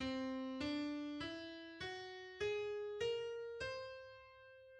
The whole tone scale built on C